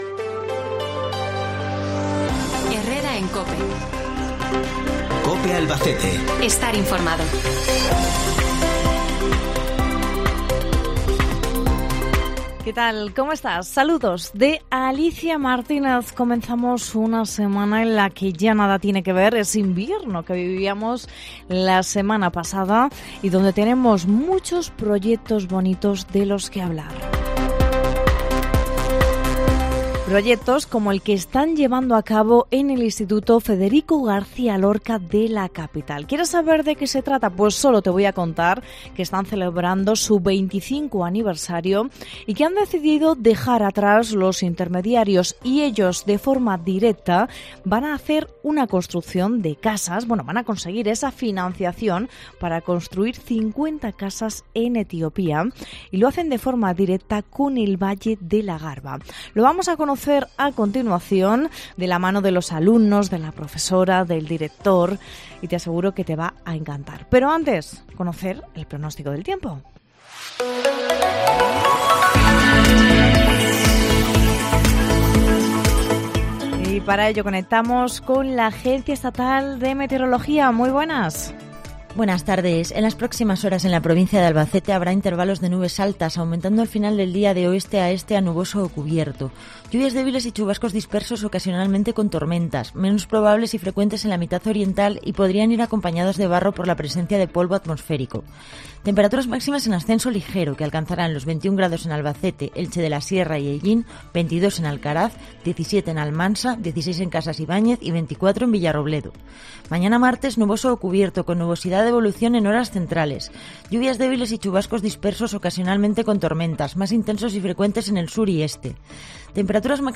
Entrevista iniciativa IES Federico García Lorca